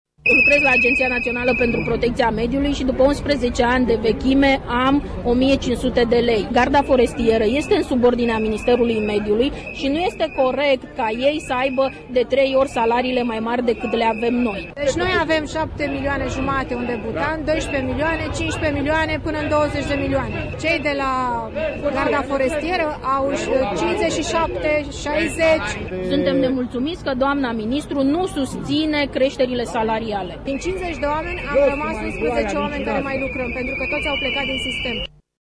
Protestatarii spun că nu este normal ca membrii Gărzii forestiere, departament înfiinţat recent, să aibă salarii triple faţă de restul angajaţilor din sistem: